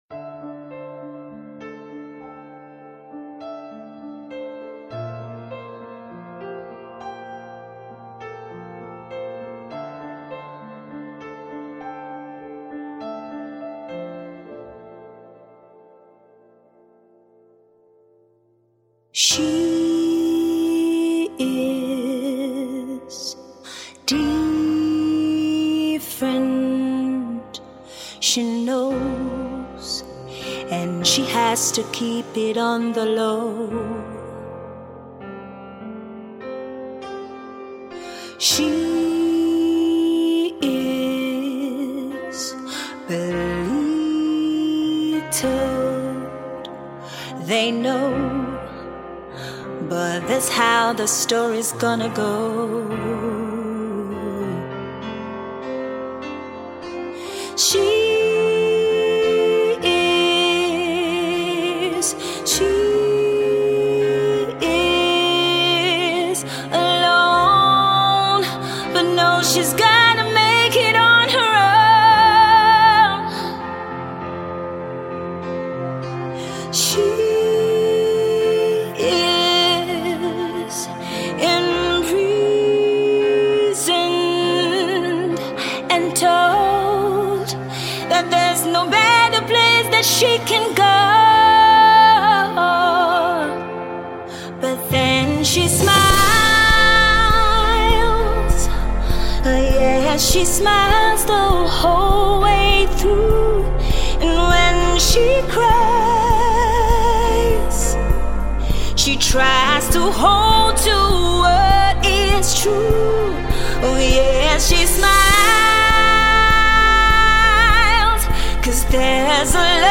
Leading Nigerian female vocalists
collaborative duet